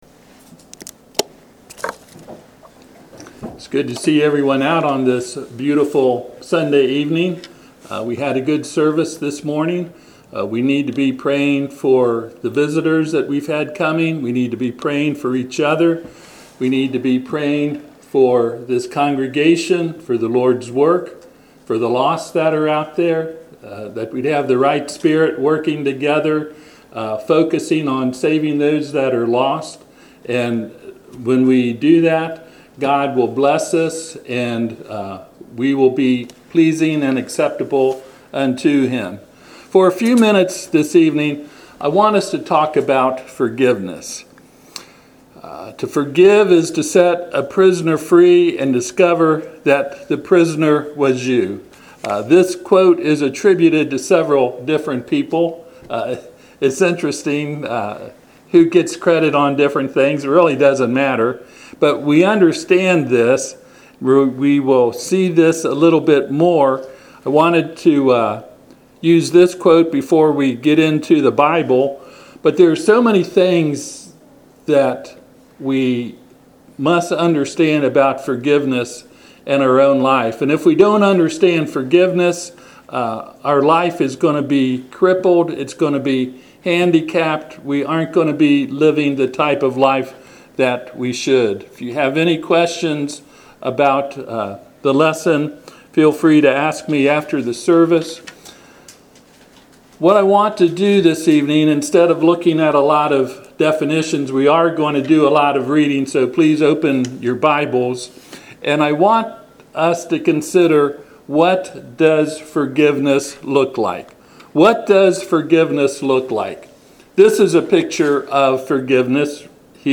Luke 15:20-24 Service Type: Sunday PM https